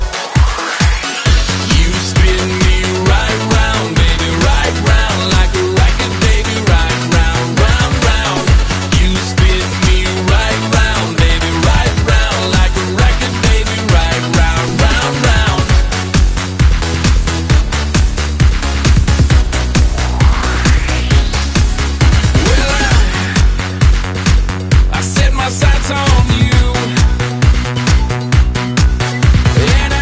Just a minecraft drowned spinning sound effects free download
Just a minecraft drowned spinning around and around while trying to attract me.